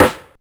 Rimshot4.aif